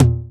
DAN02TOM1.wav